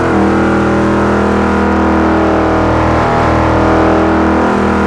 mere63_gear.wav